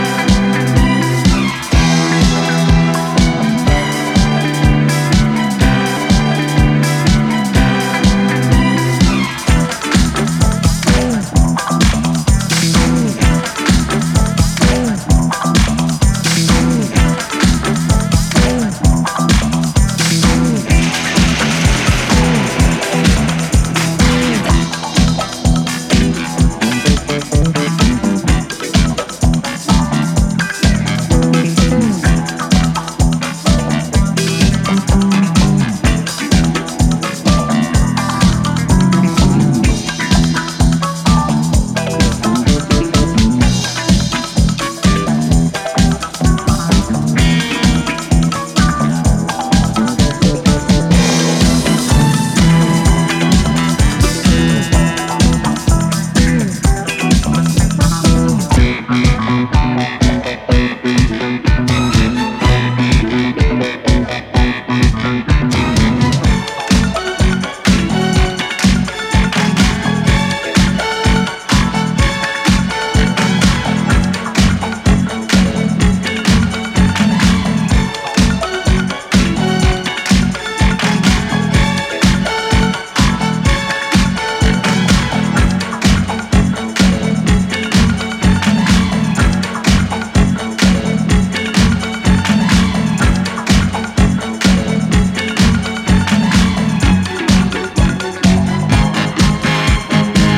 blending cosmic groove, bold beats, and Italian soul.
Disco Electronix Italo